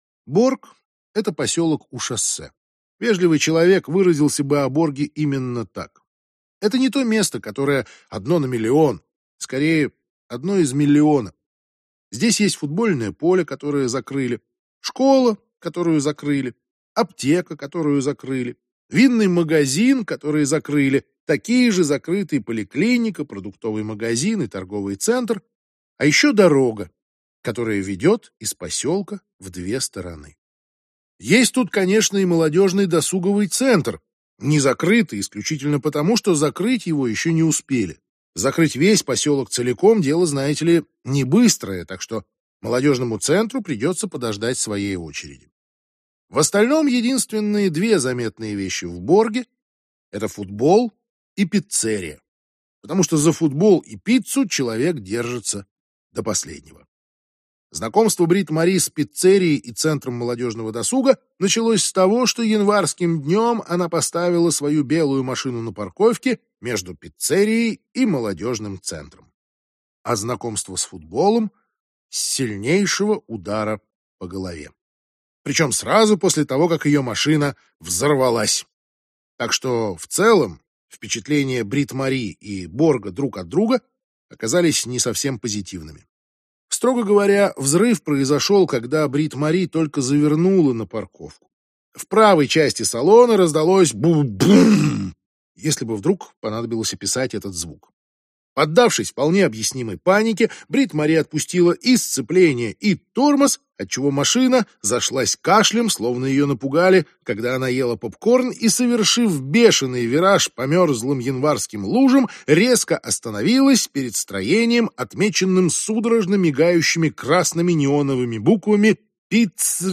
Аудиокнига Здесь была Бритт-Мари - купить, скачать и слушать онлайн | КнигоПоиск